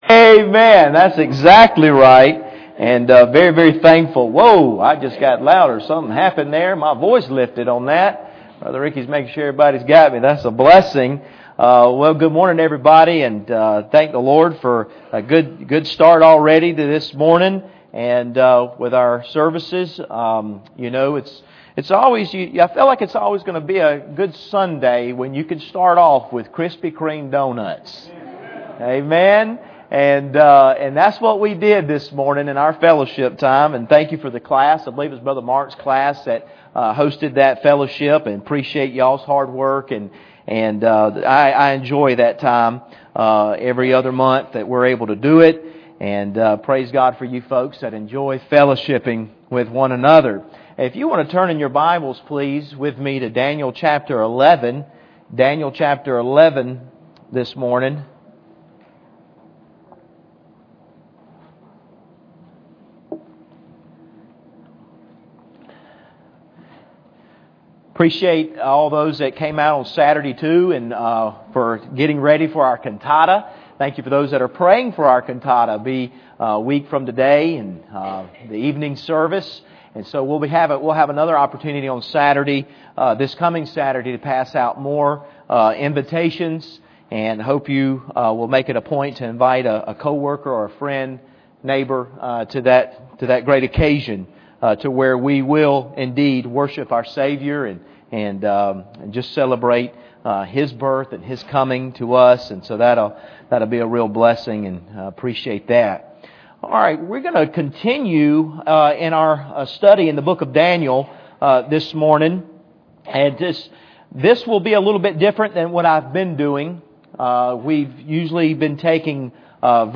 Daniel 11:32-35 Service Type: Sunday Morning Bible Text